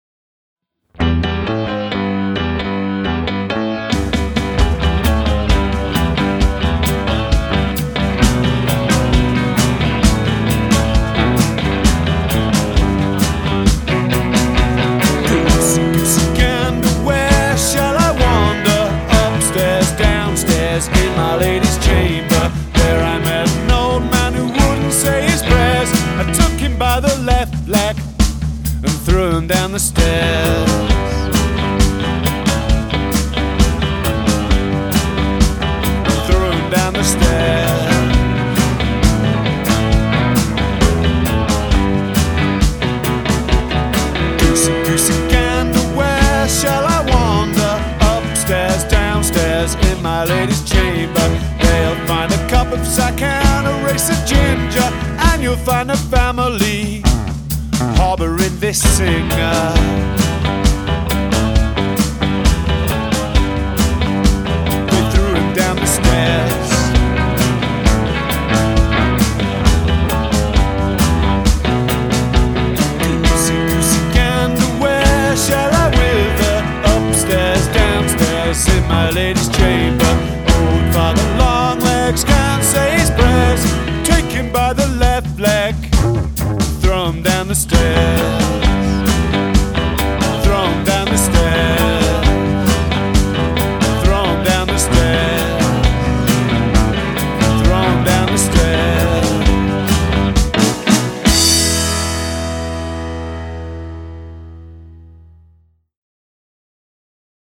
SpookyFun & Punk